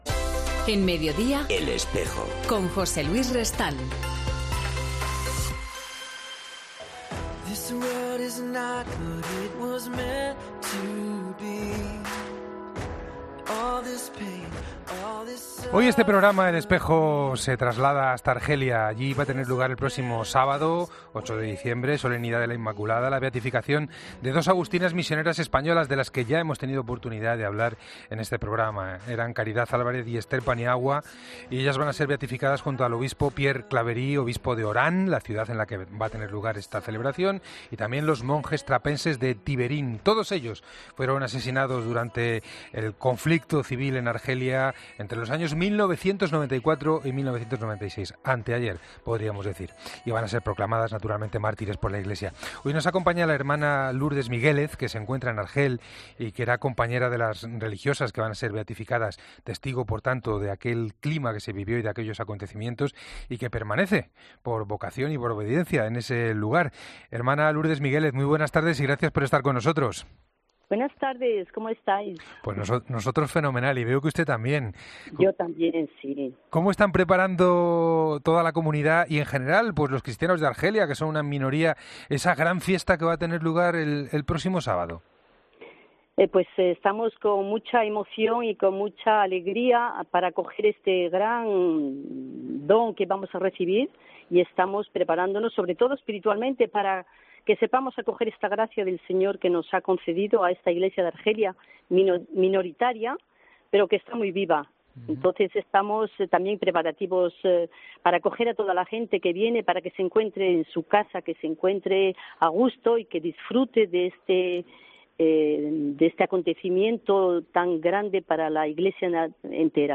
Hablamos con la agustina